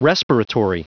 Prononciation du mot respiratory en anglais (fichier audio)
Prononciation du mot : respiratory
respiratory.wav